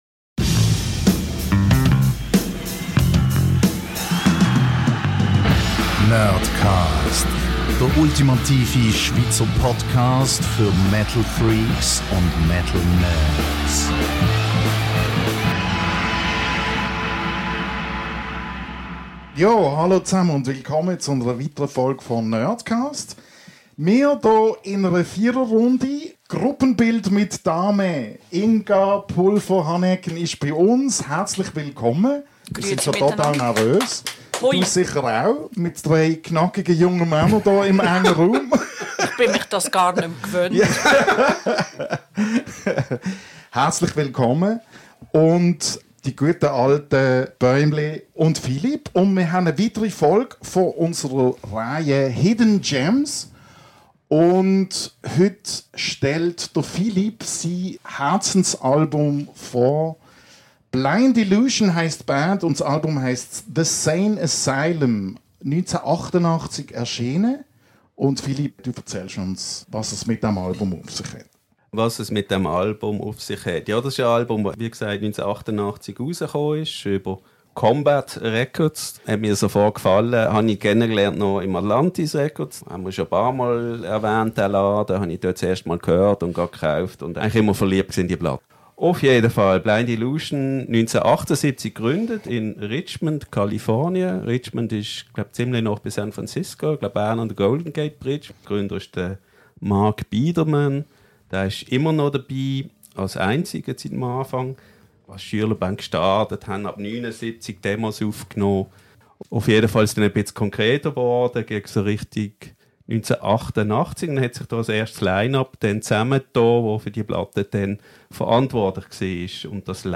Dieses Mal inklusive Soundfiles aus The Sane Asylum.